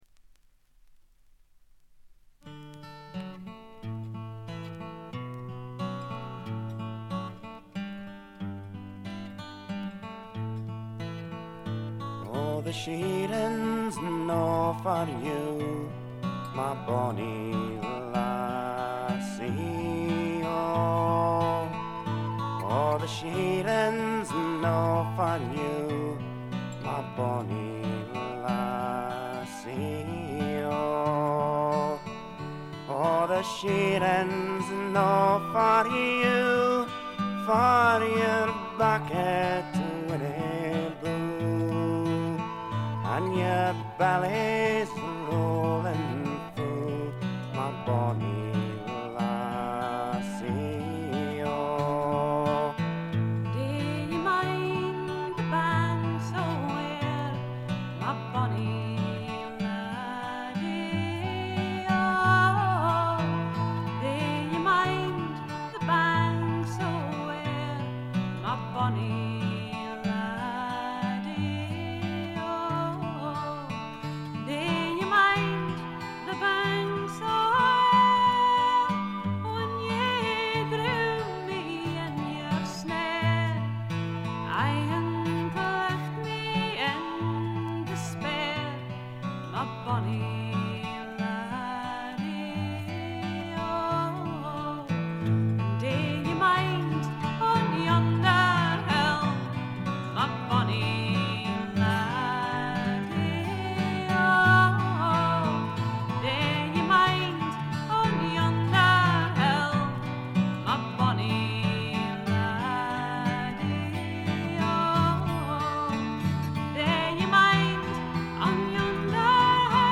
スコットランドはエディンバラの4人組トラッド・フォーク・グループ。
試聴曲は現品からの取り込み音源です。